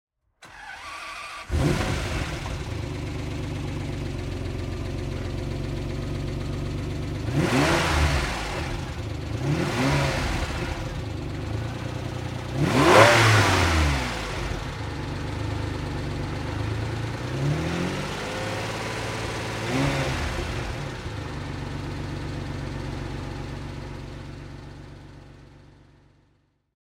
Ferrari 456 M GT (2000) - Starten und Leerlauf
Ferrari_456M_GT.mp3